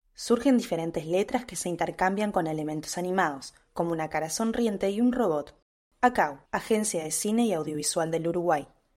Solo audiodescripción